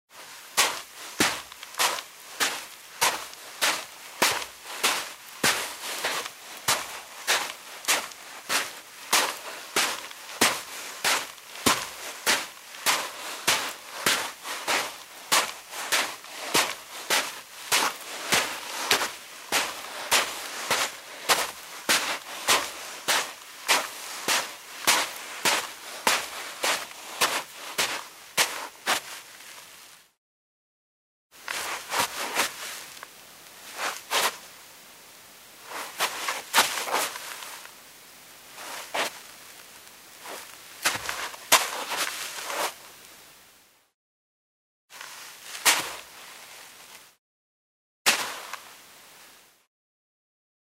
Шаги по сухому песку в туфлях — третий вариант